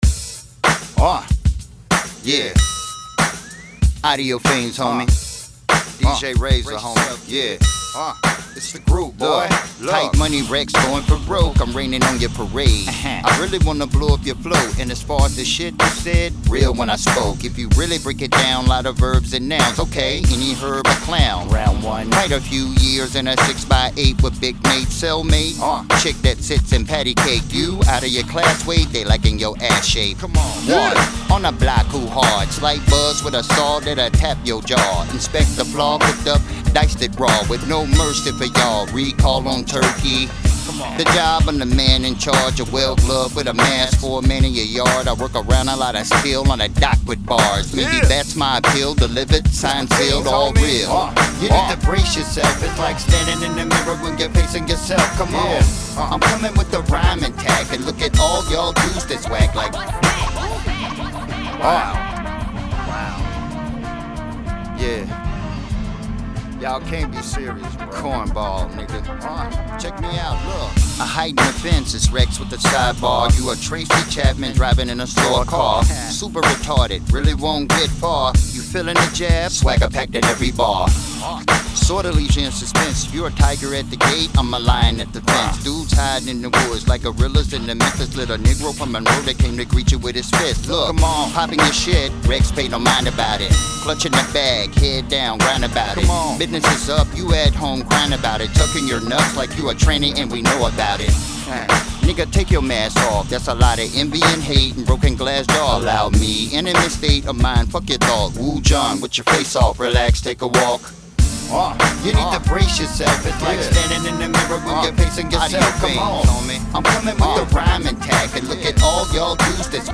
Hiphop
Description: Mid-Tempo ,HipHop, Bar Spittin Joint.